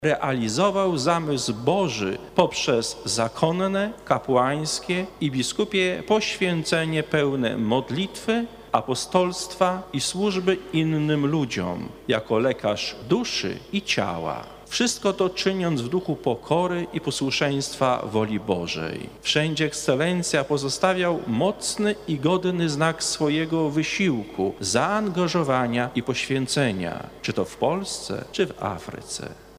Z tej okazji w katedrze świętego Michała Archanioła i świętego Floriana Męczennika odprawiona została uroczysta msza święta z udziałem biskupów metropolii warszawskiej, na czele z kardynałem Kazimierzem Nyczem i abp. Tadeuszem Wojdą.
Składając życzenia jubilatowi metropolita warszawski kard. Nycz wyraził uznanie dla rozległej wiedzy, szerokiego spojrzenia na życie Kościoła i zjawisk zachodzących w społeczeństwie oraz życiowych dokonań.